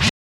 Roland.Juno.D _ Limited Edition _ GM2 SFX Kit _ 01.wav